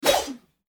attack_blade_wp_1.mp3